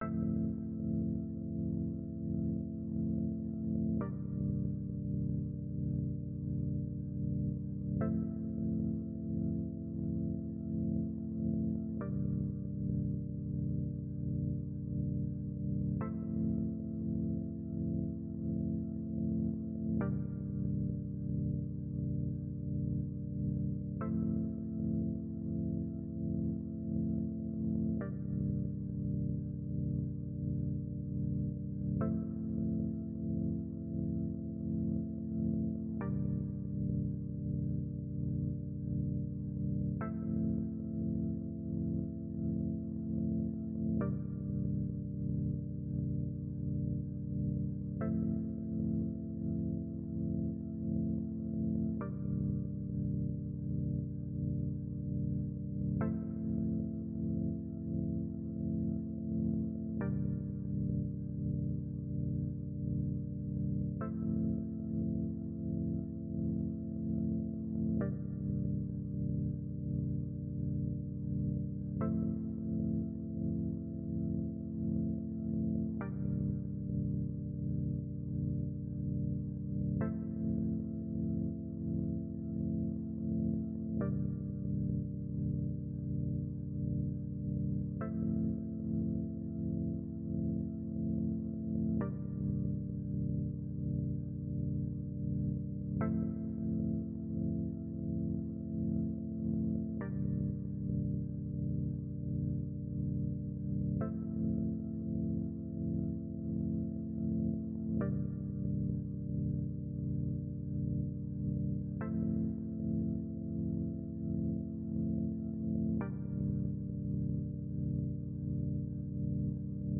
• カテゴリー: 落ち着きと鎮め、リラクゼーション